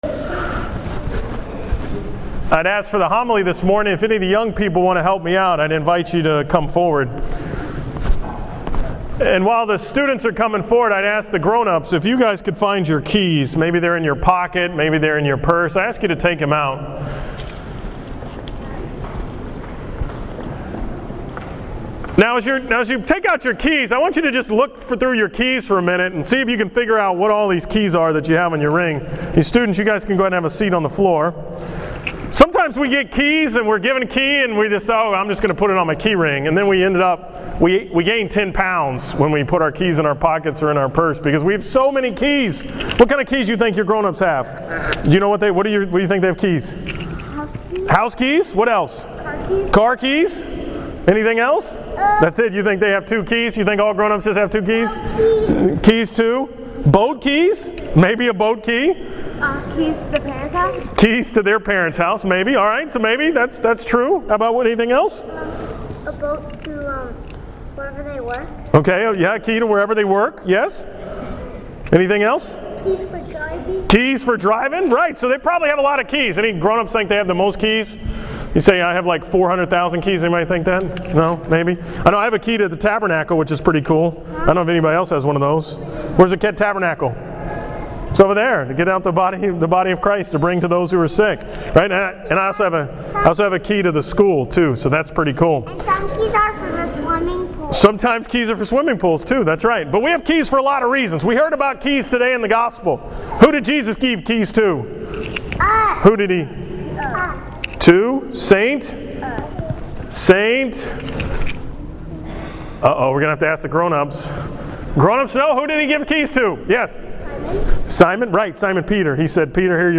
From the 9 am Mass on the Feast of St. Peter and Paul